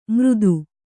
♪ mřdu